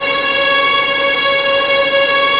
Index of /90_sSampleCDs/Roland L-CD702/VOL-1/STR_Vlns Bow FX/STR_Vls Sul Pont